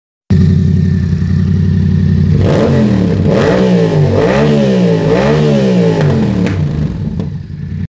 Le son est tellement mieux sans chicane mais pas le droit !